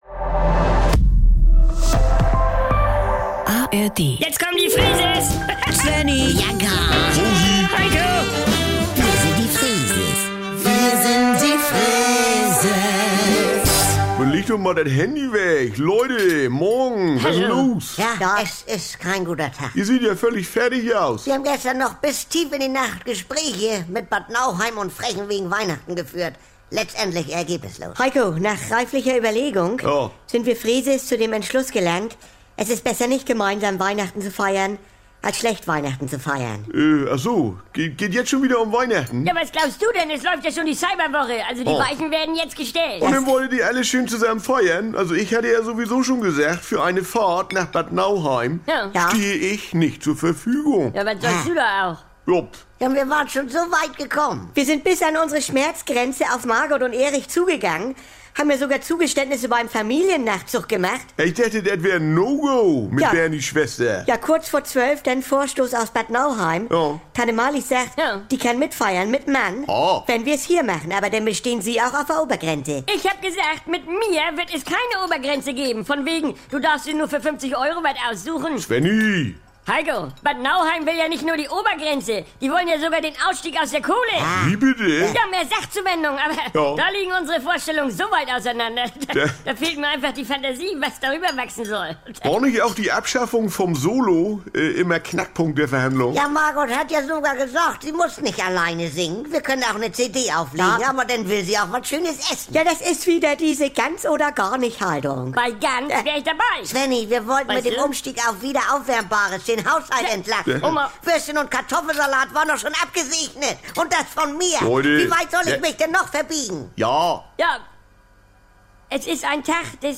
Jederzeit und so oft ihr wollt: Die NDR 2 Kult-Comedy direkt aus